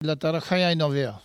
Langue Maraîchin